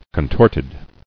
[con·tort·ed]